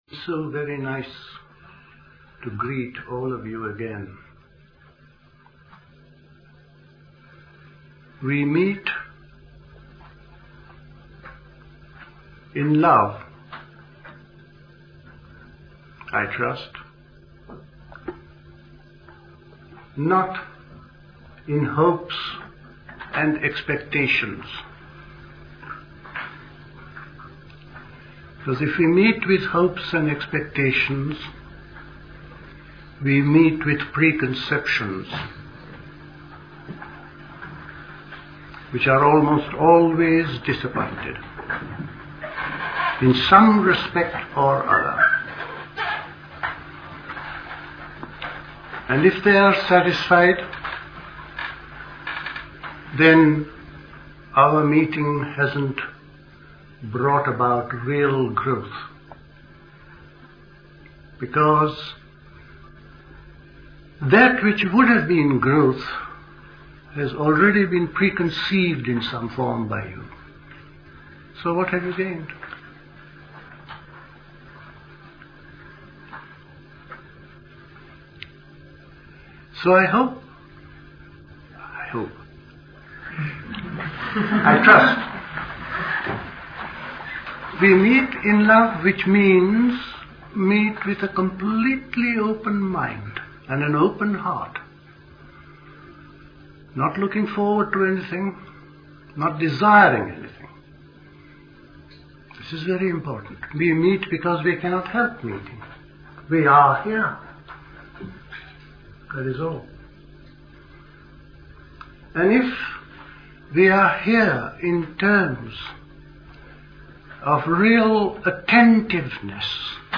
Recorded at the 1973 Buddhist Summer School.